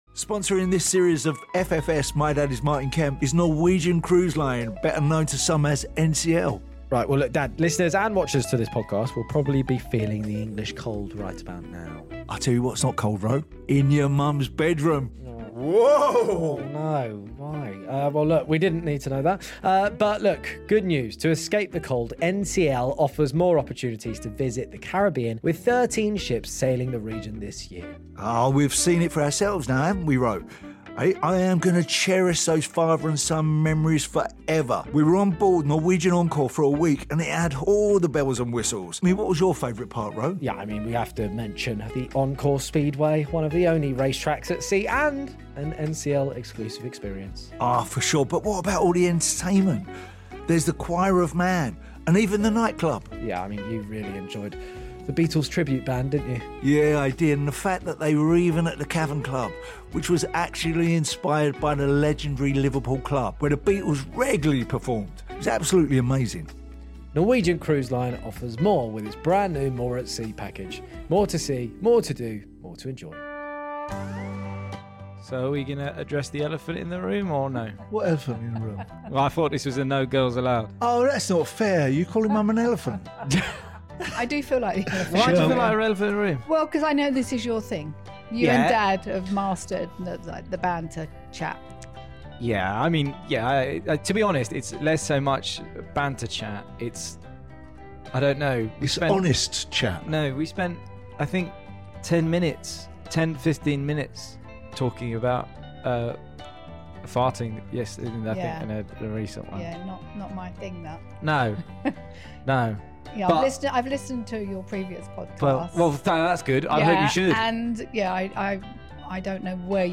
Get ready for an unforgettable journey with Martin, Roman and Shirlie as they reveal the wildest, most unbelievable holiday stories in this exclusive episode from a cruise ship for FFS! My Dad is Martin Kemp.